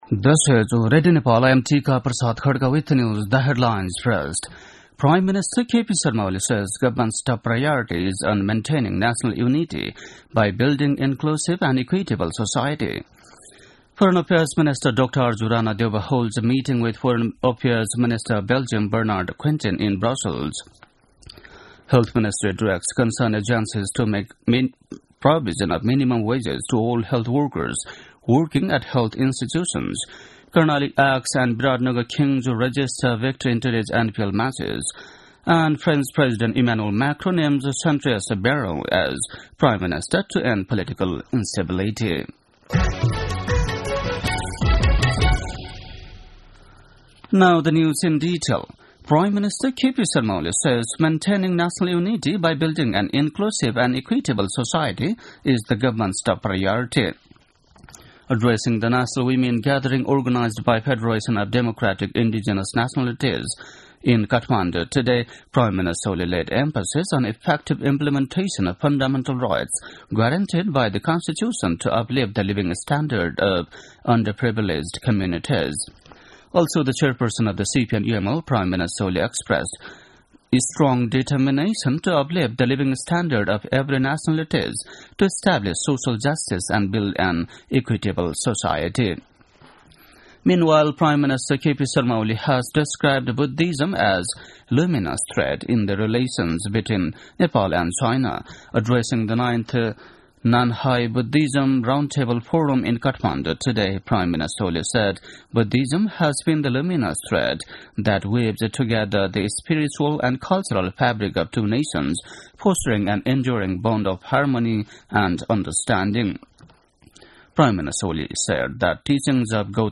बेलुकी ८ बजेको अङ्ग्रेजी समाचार : २९ मंसिर , २०८१
8-pm-english-news-8-28.mp3